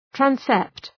{‘trænsept}